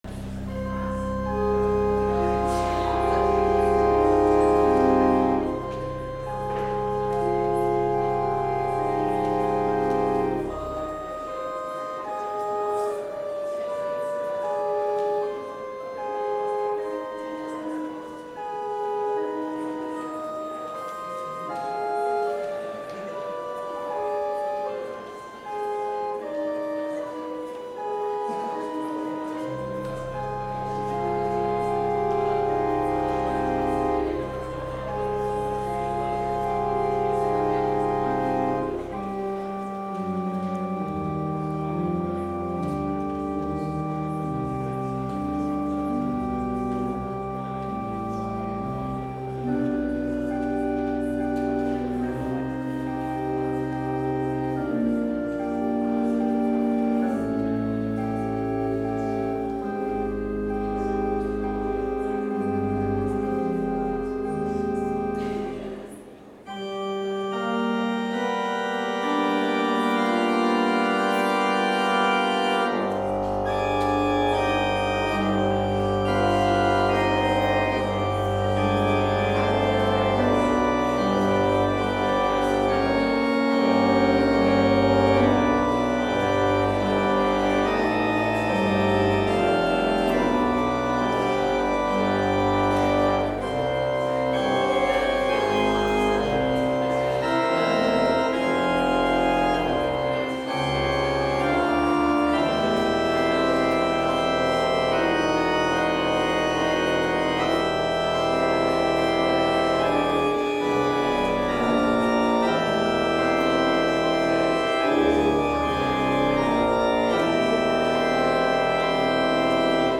Complete service audio for Chapel - November 2, 2021